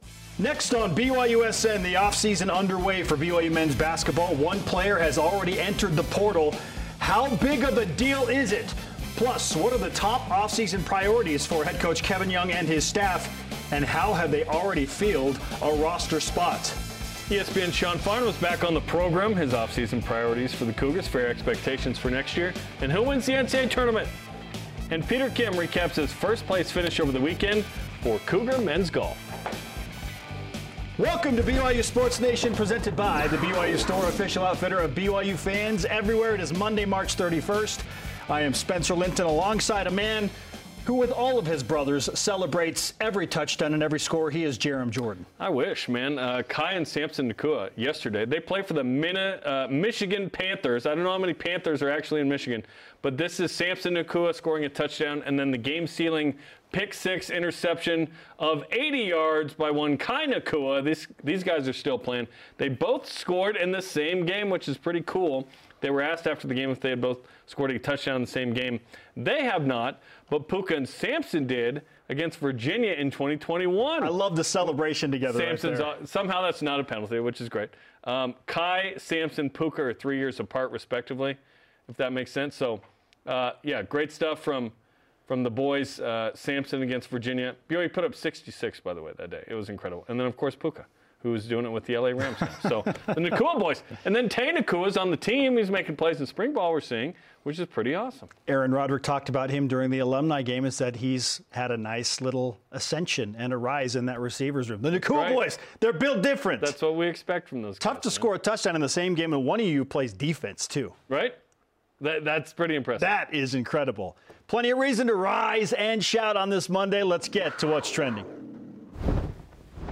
You’ll get in-depth play analysis, athlete interviews, and gripping commentary on all things BYU Football, Basketball, and beyond.